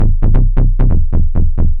• 133 bass mid techno ice - F.wav